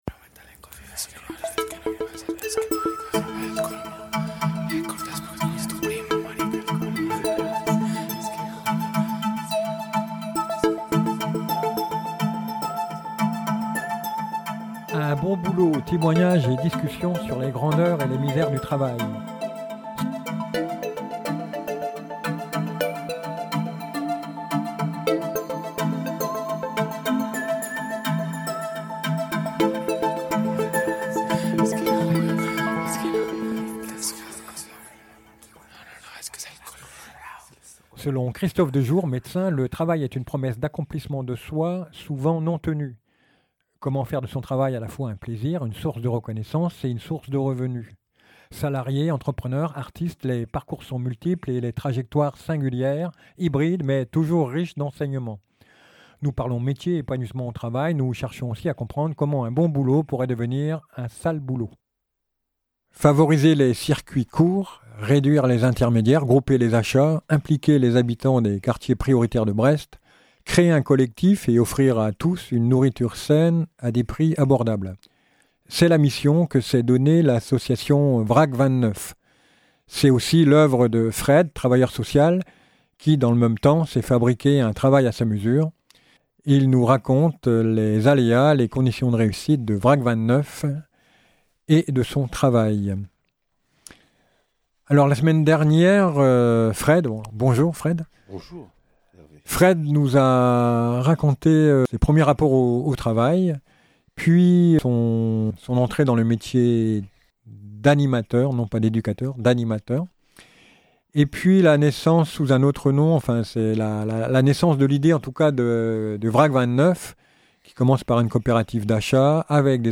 Dans ce 2e entretien Il nous raconte le fonctionnement, les aléas et les conditions de réussite de cette association et de son travail.